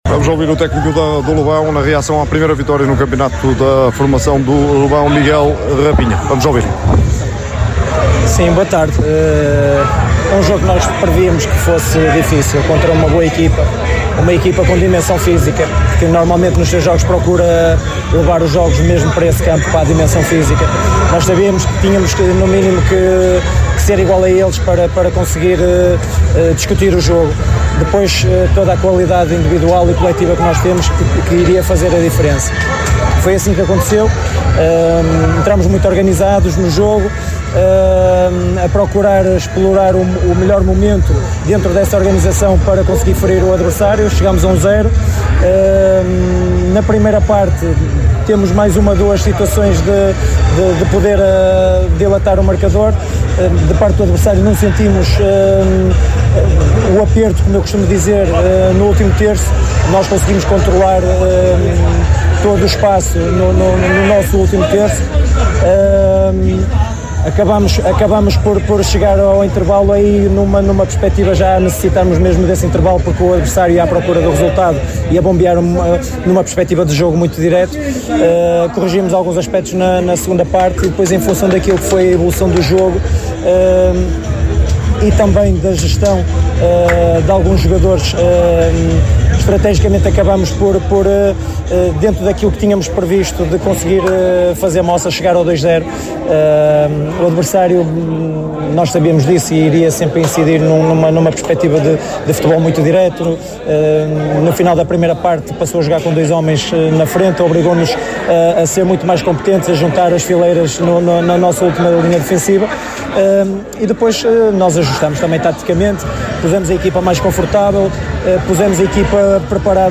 ouviu os protagonistas da partida no final.